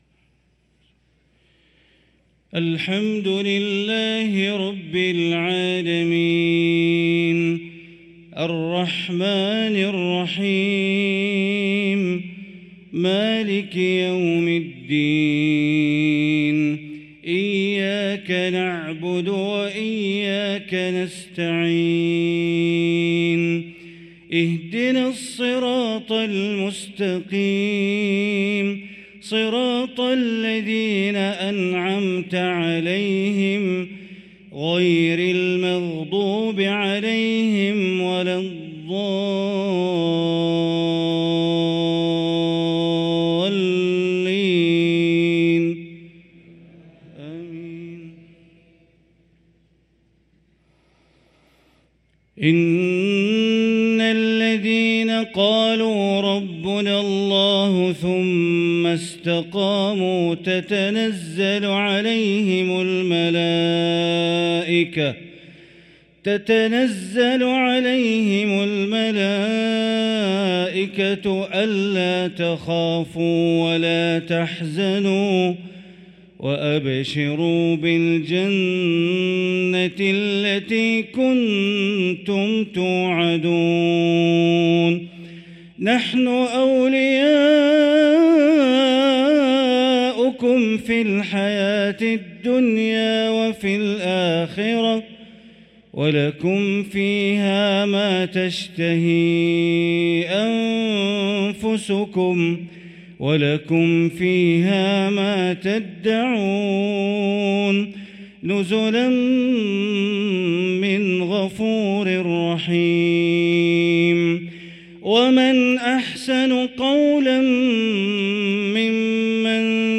صلاة العشاء للقارئ بندر بليلة 24 ربيع الأول 1445 هـ
تِلَاوَات الْحَرَمَيْن .